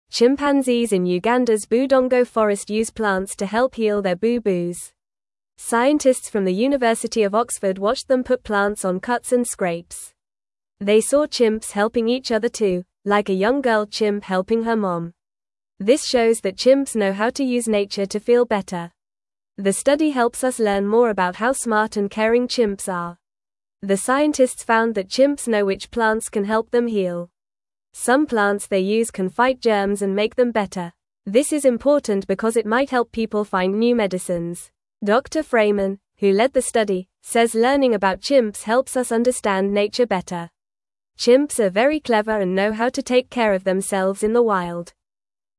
Fast
English-Newsroom-Beginner-FAST-Reading-Chimps-Use-Plants-to-Heal-Their-Boo-Boos.mp3